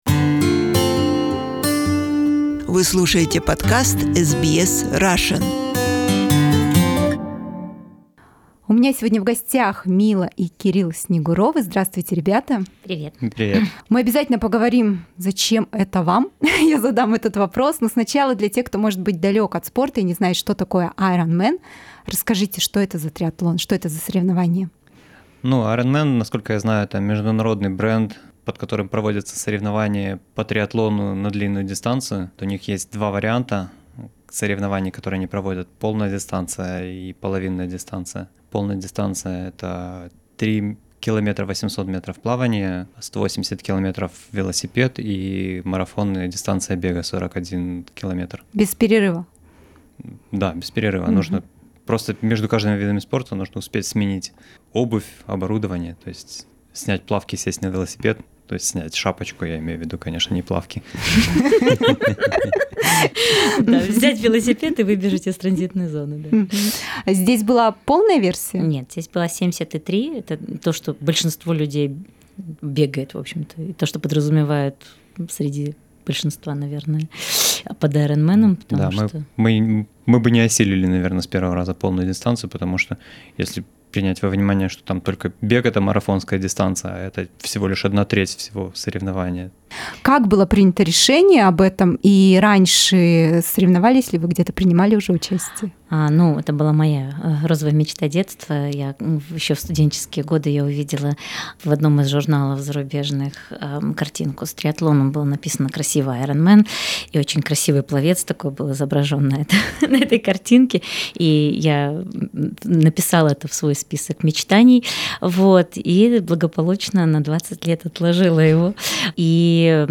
а сегодня уже приехали в нашу студию поделиться впечатлениями.